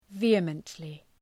Shkrimi fonetik {‘vi:əməntlı}
vehemently.mp3